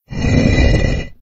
end_of_level.ogg